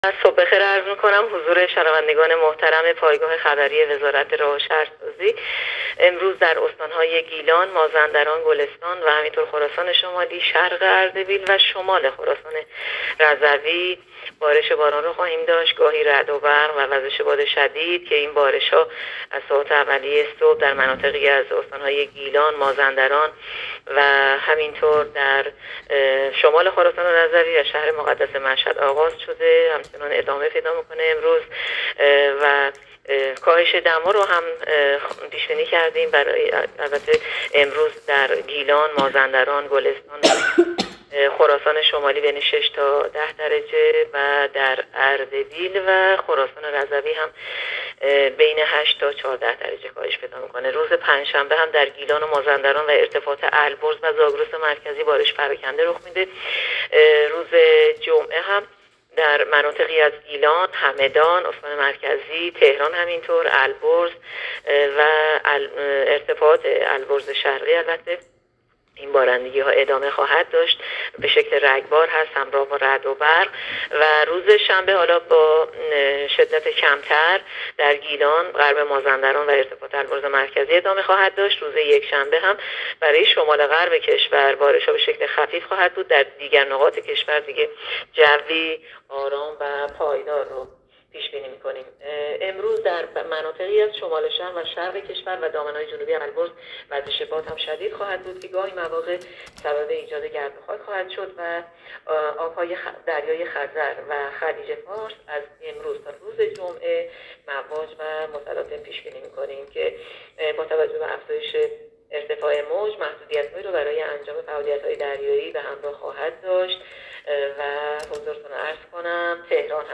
گزارش رادیو اینترنتی پایگاه‌ خبری از آخرین وضعیت آب‌وهوای ۱۶ آبان؛